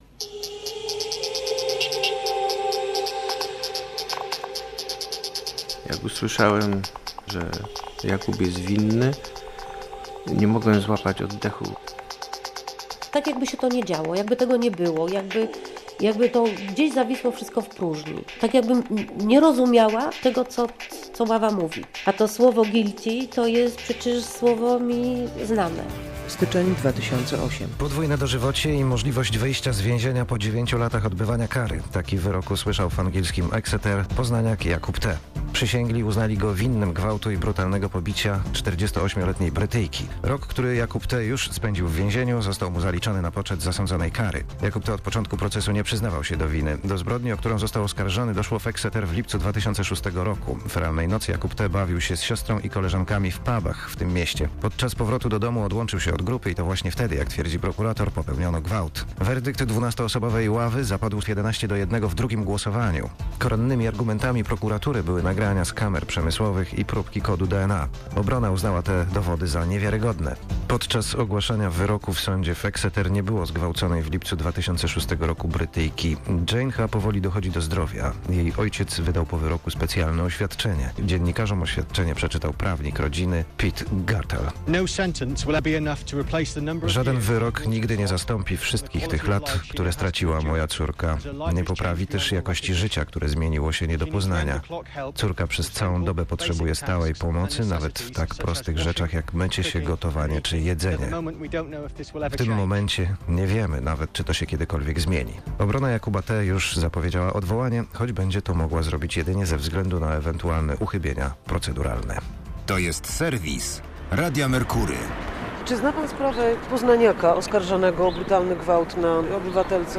reportaż - Radio Poznań